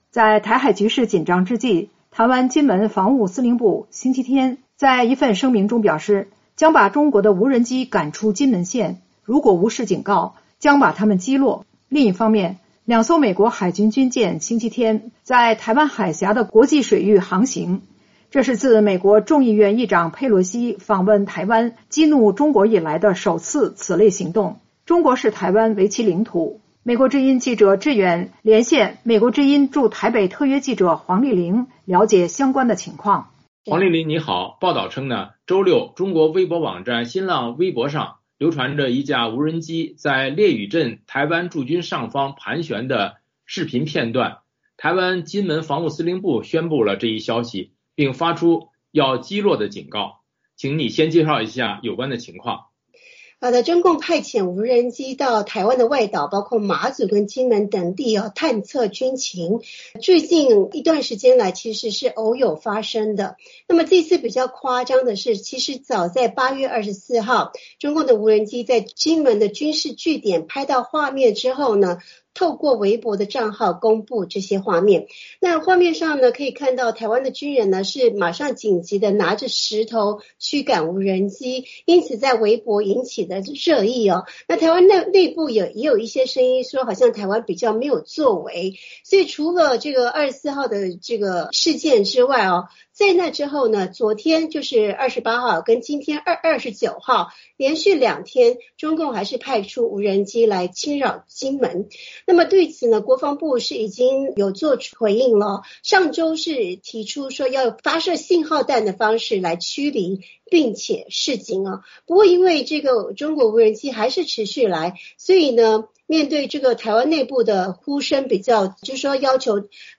VOA连线：记者连线：针对中国无人机的持续进犯 台湾国防部正式升高警戒